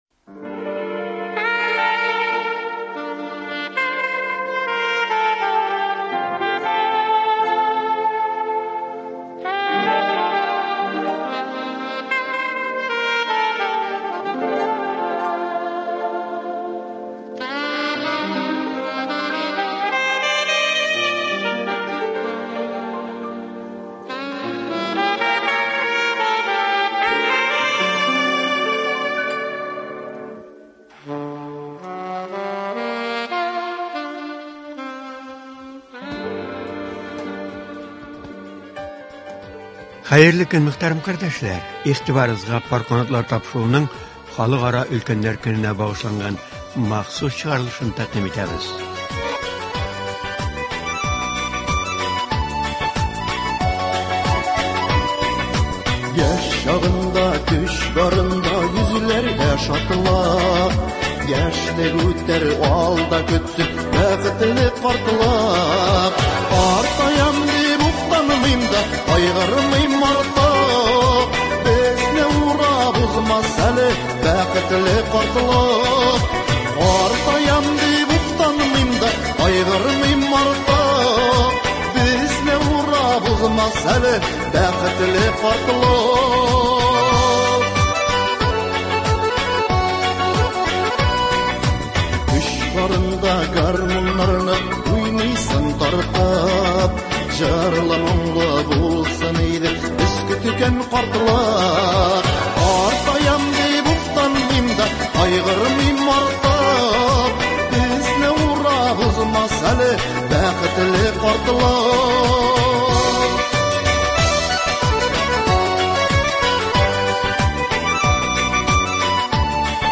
Ә караучысы булмаган өлкән яшьтәге кешеләр бу көнне ничек үткәрә, алар өчен нинди шартлар тудырылган? Саба районының Лесхоз бистәсендә урнашкан картлар йортыннан әзерләнгән репортажда шулар хакында сүз бара.